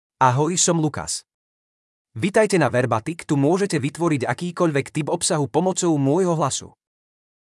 Lukas — Male Slovak (Slovakia) AI Voice | TTS, Voice Cloning & Video | Verbatik AI
MaleSlovak (Slovakia)
Voice sample
Male
Lukas delivers clear pronunciation with authentic Slovakia Slovak intonation, making your content sound professionally produced.